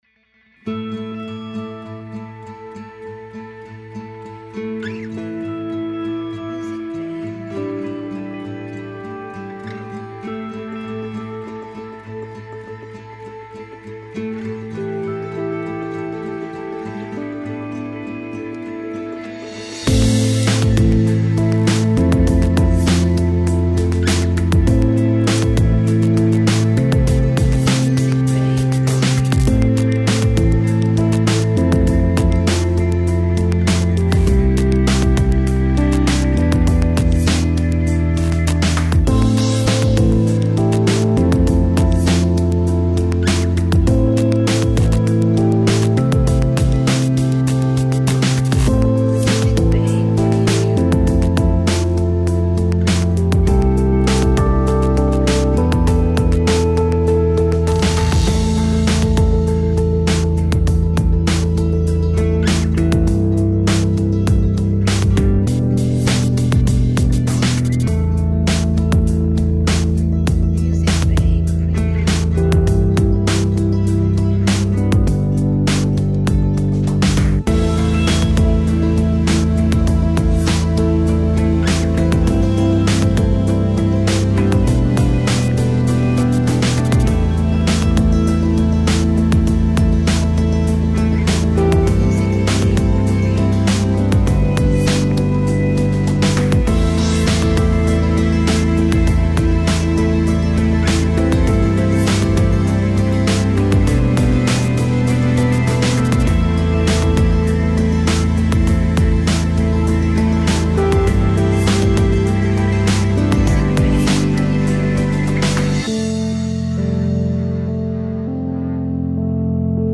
A category of tags that highlights dark mood.